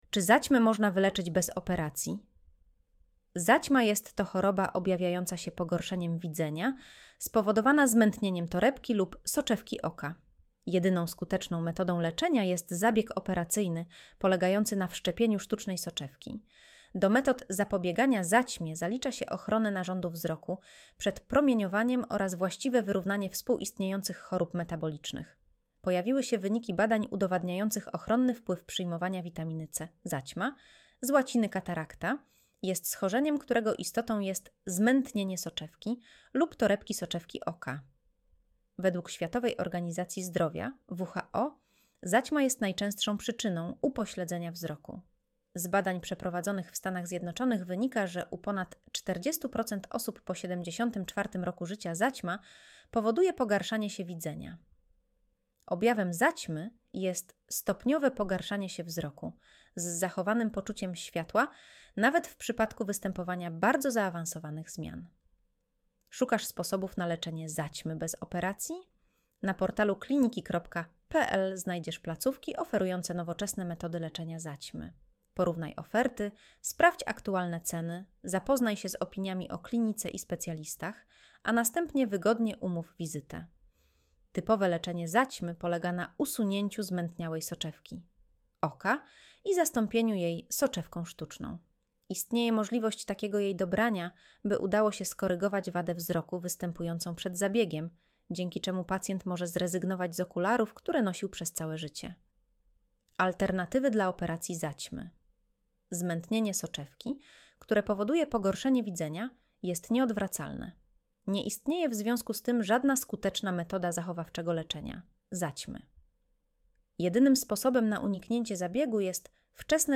Audio wygenerowane przez AI, może zawierać błędy